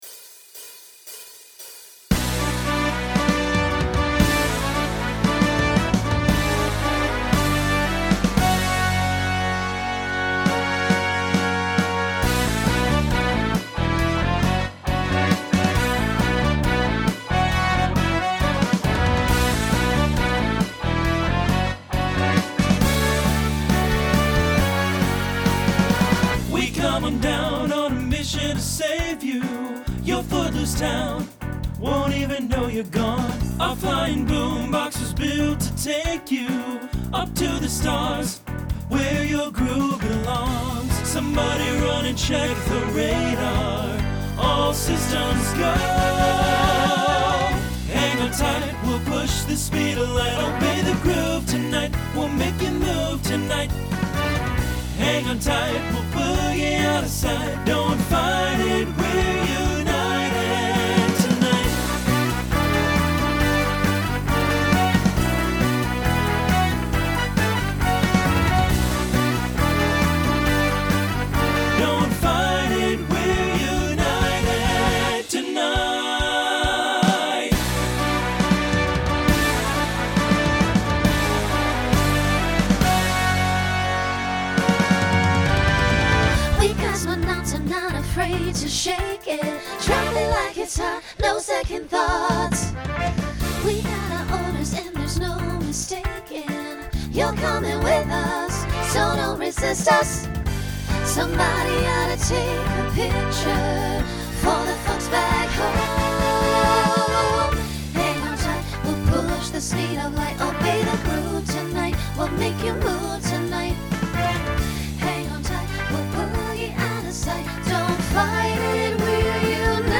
Genre Rock Instrumental combo
Transition Voicing Mixed